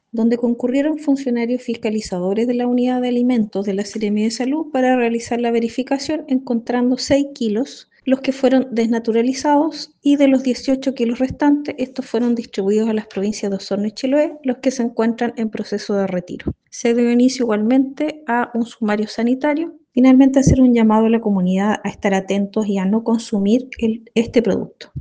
La seremi (s) de Salud de Los Lagos, Sofía Torres, indicó que durante la jornada de ayer se notificó la existencia de estos productos en una bodega en Puerto Montt.